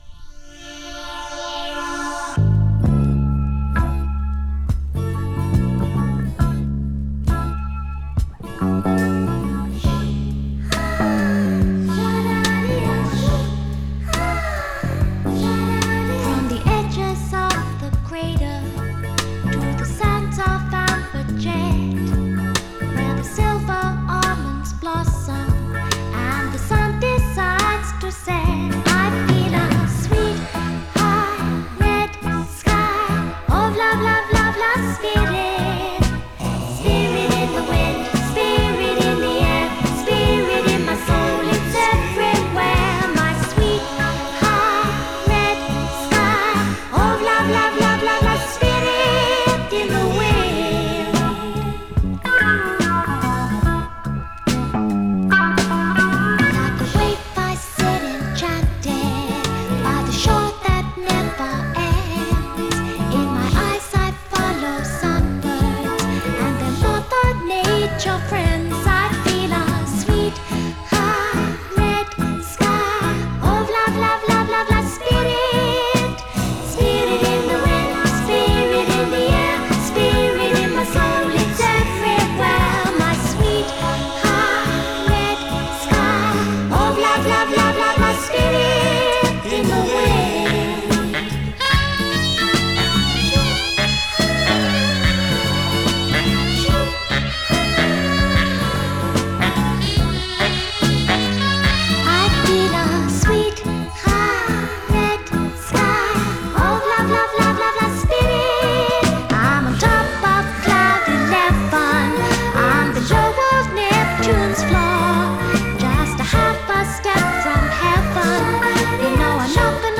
Mellow Rock
UK産ポップ・グループ。
【PSYCHEDELIC】【POPS】